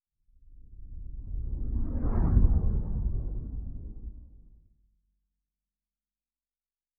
Sci-Fi Sounds
Distant Ship Pass By 4_5.wav